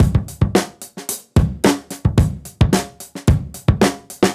Index of /musicradar/dusty-funk-samples/Beats/110bpm
DF_BeatD_110-03.wav